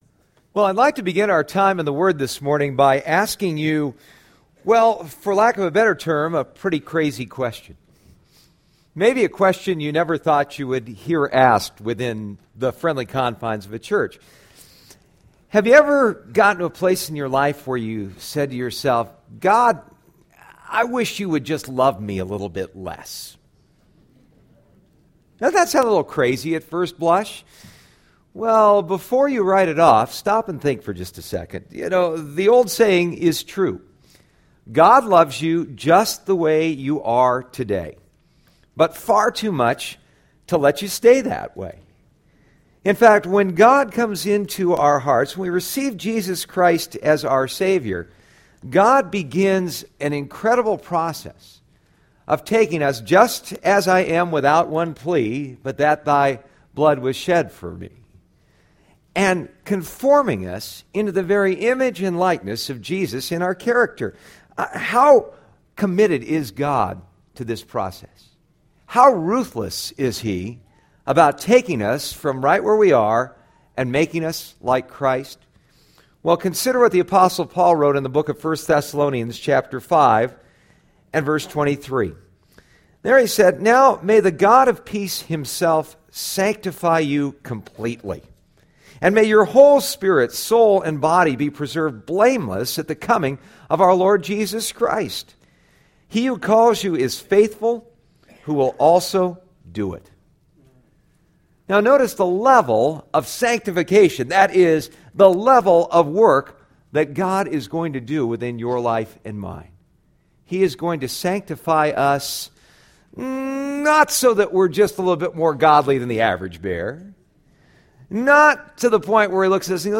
Psalm 39 Service Type: Sunday Morning « The Song You Were Born to Sing Making Sense of Suffering